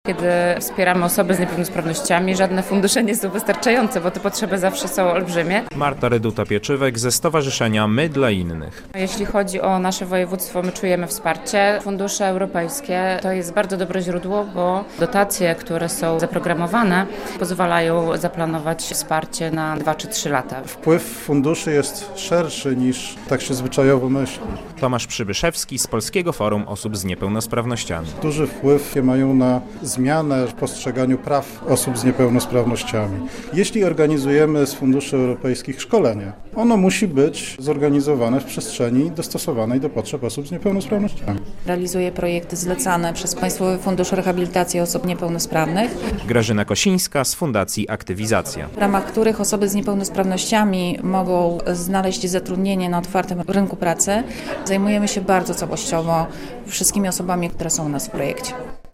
Konferencja fundusze europejskie dla osób z niepełnosprawnościami - relacja